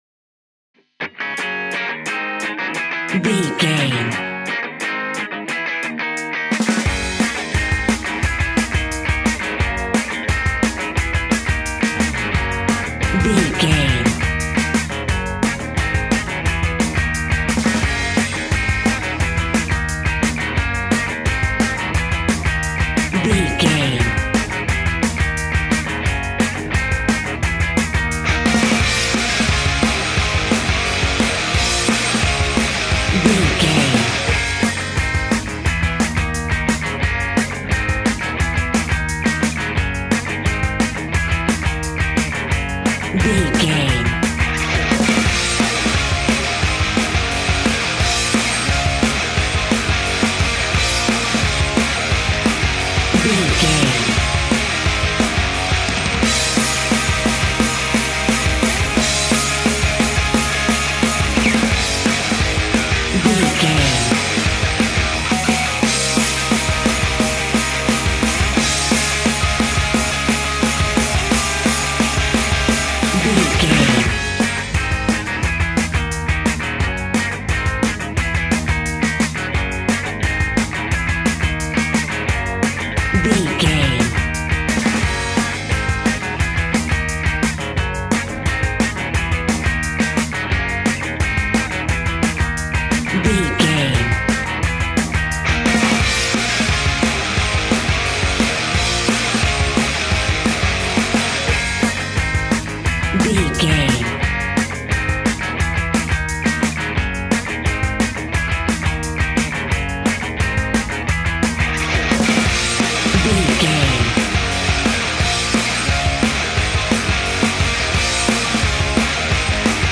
Ionian/Major
E♭
Fast
energetic
driving
heavy
aggressive
electric guitar
bass guitar
drums
metal
hard rock
goth
scary rock
rock instrumentals